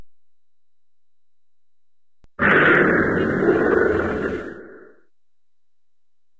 Dinosaur King Tyrannosaurus Roar
Category: Sound FX   Right: Personal